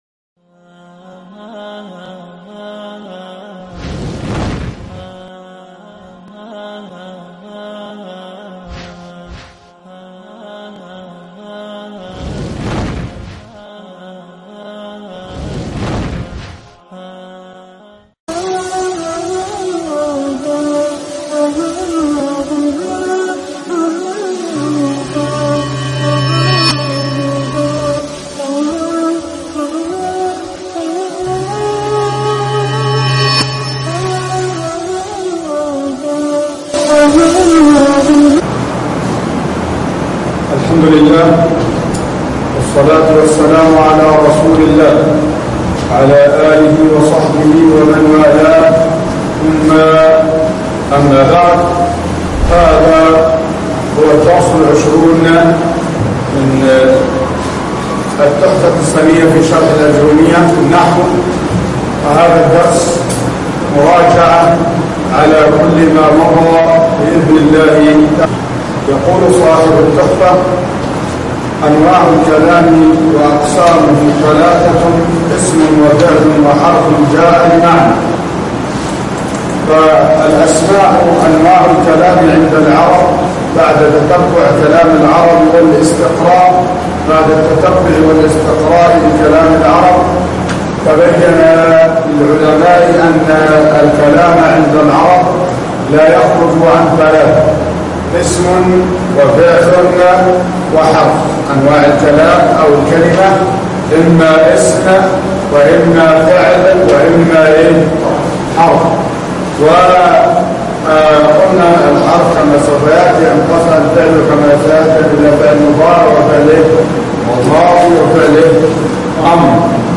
الدرس 20 مراجعة عامة ( التحفة السنية شرح كتاب الآجرومية )